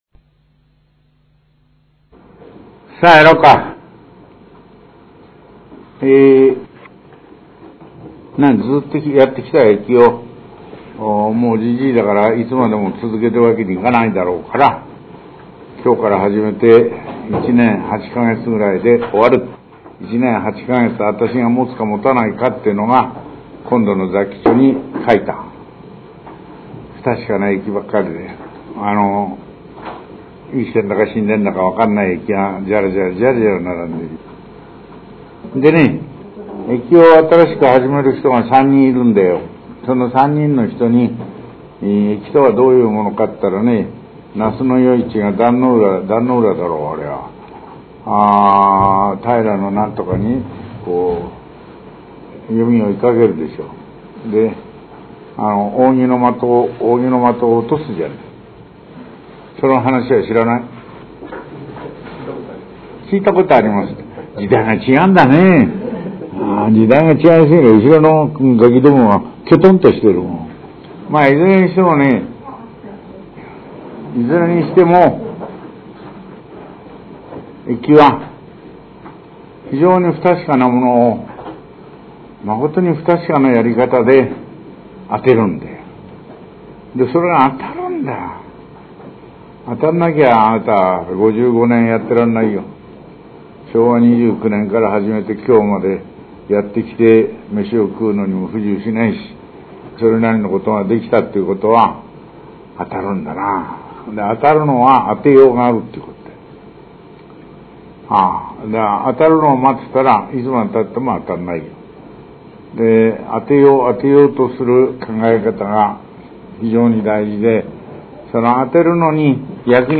全64巻 各巻7,350円（税込） ＊火天大有のみ創刊特別価格 3,675円（税込） CD2枚組＋解説書（小冊子）2冊 収録時間約90分（各巻ごとに異なります） ＊実際の講義をライブ収録しているため、一部音切れ、音声の乱れ、外部からの騒音、聞き取りにくい個所などがあります。